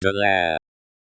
The Lair? So many questions, so little time…